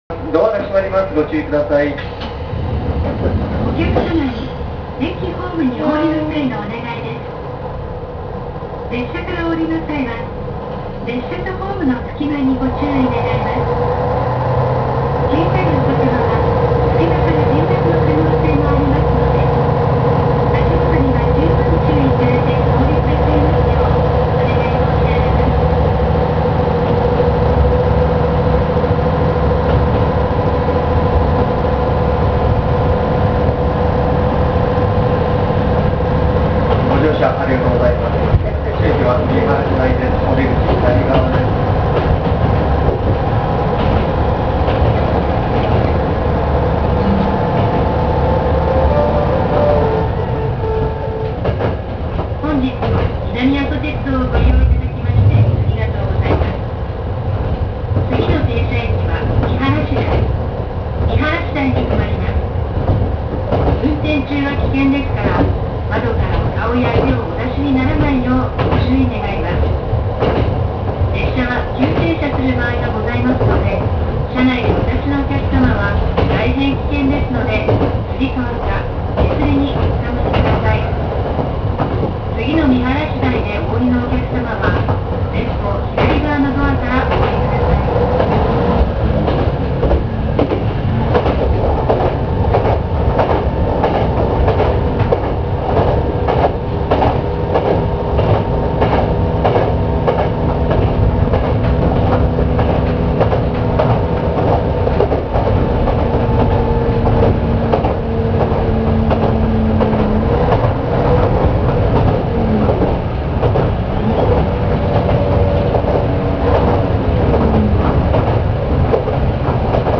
MT-3010形走行音
【高森線】高森→見晴台（2分30秒：818KB）
基本的にMT-3000形と同じ音になります。車内放送の冒頭には熊本の民謡である「おてもやん」を少しアレンジしたメロディが流れます。